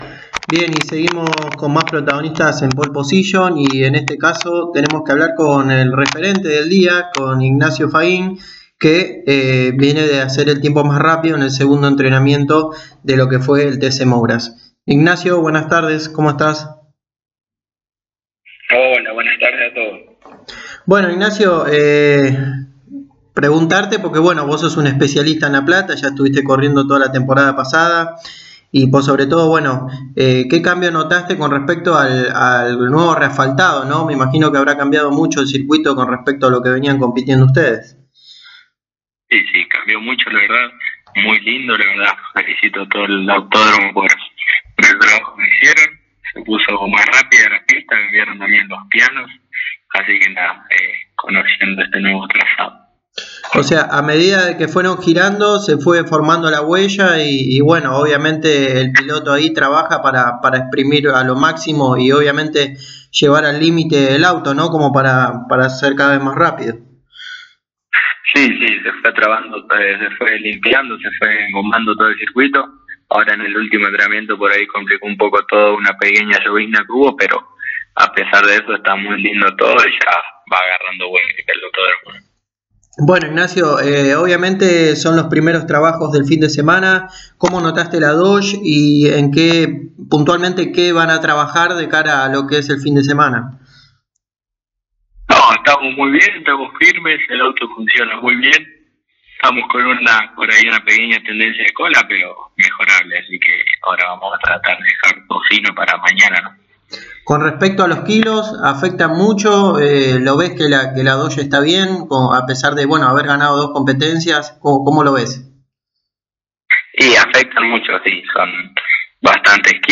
El santafesino pasó por los micrófonos de Pole Position y habló sobre el buen inició de actividad en La Plata. Habló sobre el buen funcionamiento de la Dodge y sobre el reasfaltado del Autódromo platense.